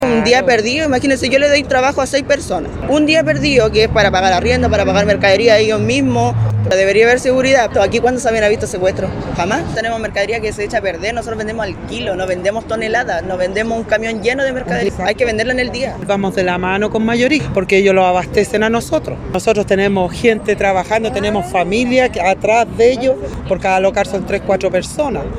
Una locataria minorista explicó que ellos venden día a día, y no lo hacen, su mercadería vence y se pierde.